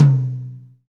Index of /90_sSampleCDs/Northstar - Drumscapes Roland/DRM_Pop_Country/KIT_P_C Wet 1 x
TOM P C H19R.wav